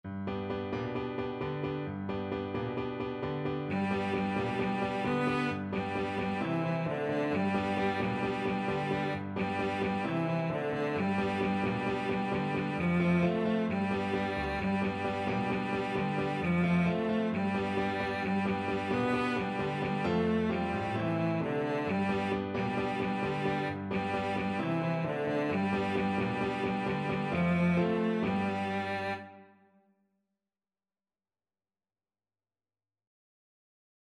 Cello
G major (Sounding Pitch) (View more G major Music for Cello )
4/4 (View more 4/4 Music)
Fast =c.132
Caribbean Music for Cello